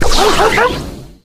buzz_ulti_dog_atk_01.ogg